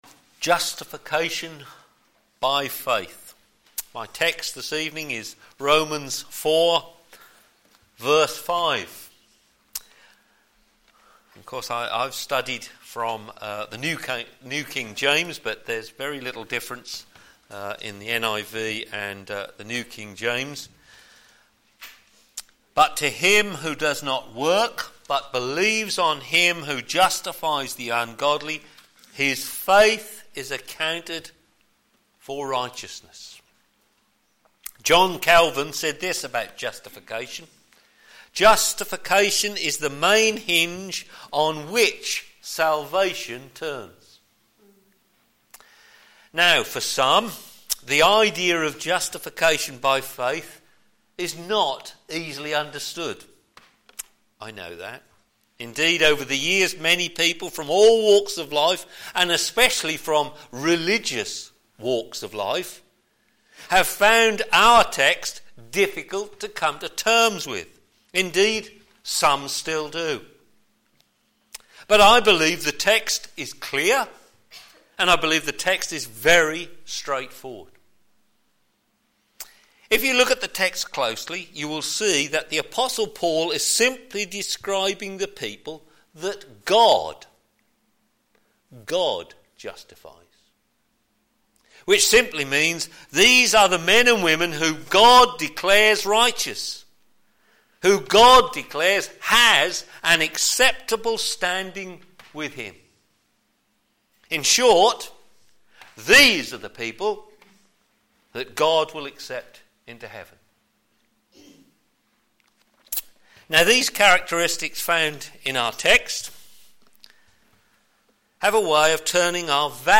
p.m. Service
Theme: Justification by faith Sermon